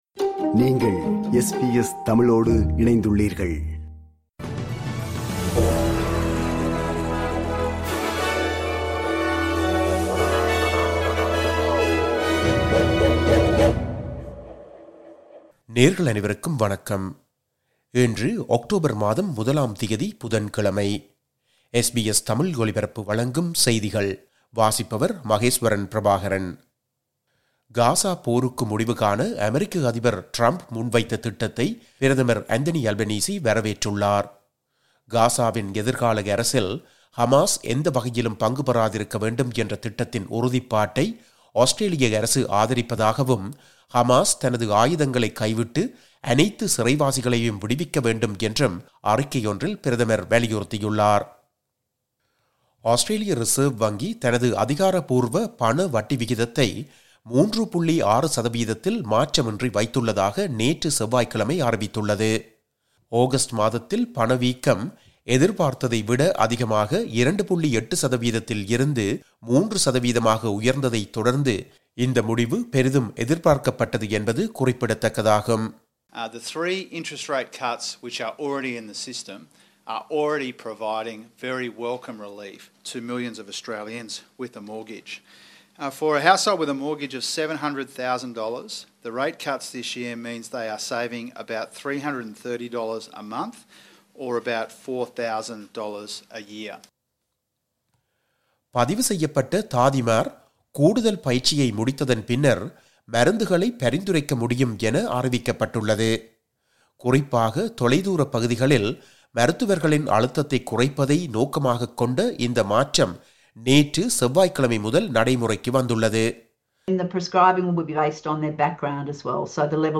இன்றைய செய்திகள்: 01 அக்டோபர் 2025 புதன்கிழமை
SBS தமிழ் ஒலிபரப்பின் இன்றைய (புதன்கிழமை 01/10/2025) செய்திகள்.